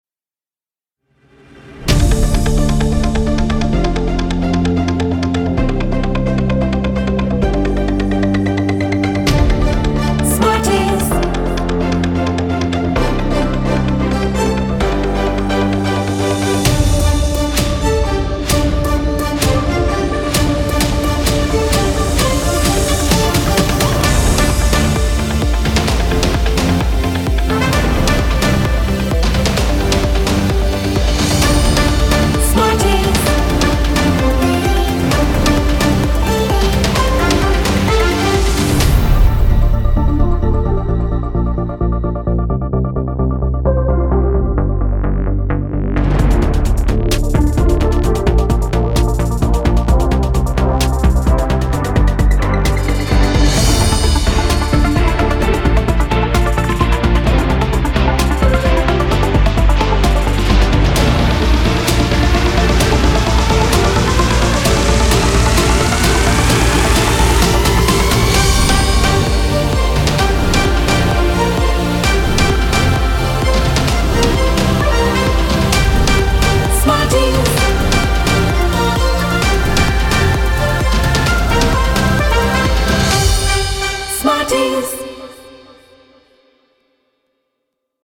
a new audio identity